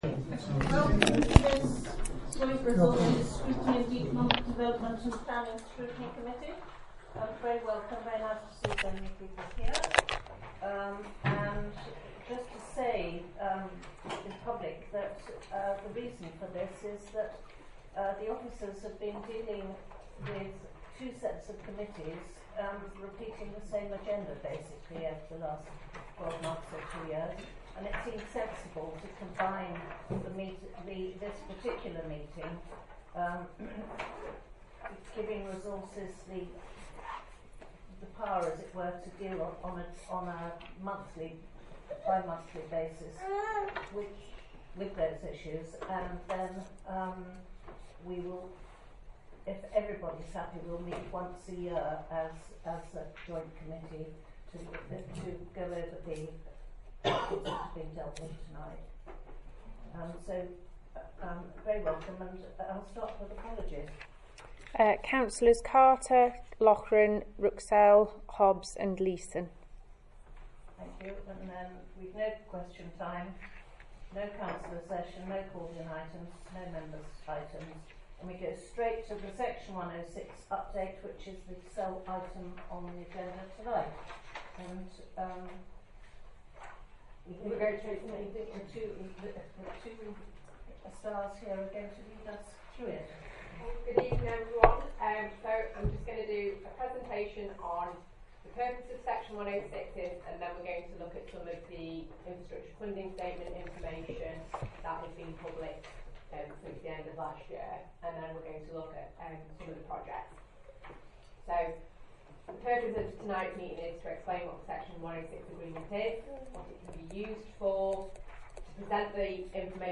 Location: Craddock Room, Civic Centre, Riverside, Stafford